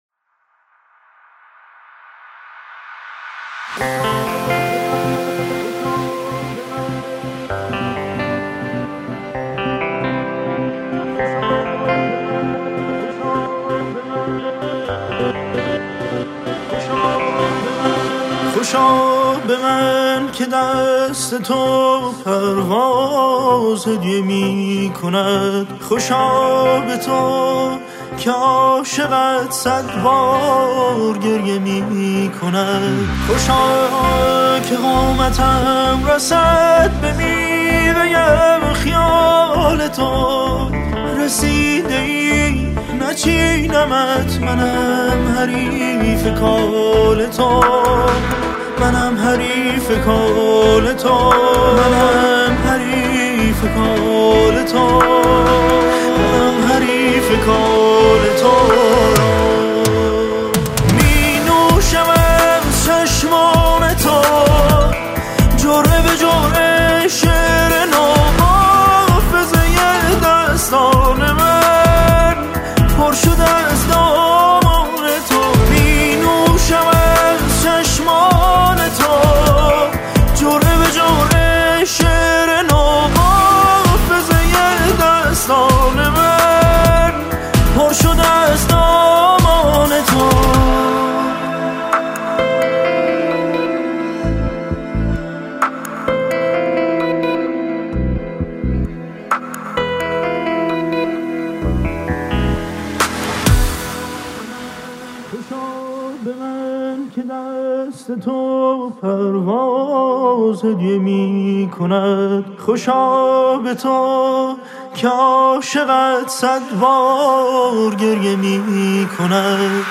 • دسته بندی ایرانی پاپ